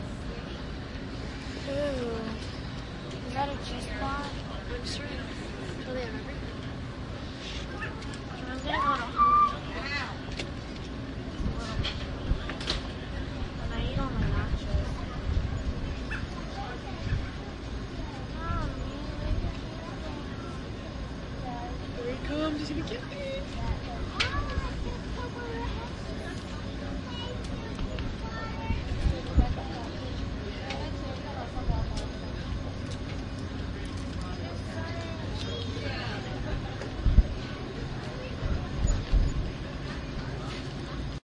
描述：用DS40录制的来自梅角刘易斯渡轮右舷甲板的空栏，并在Wavosaur中进行编辑。
Tag: 斗篷可 - 刘易斯 - 轮渡 特拉华州 实地记录 新球衣 海洋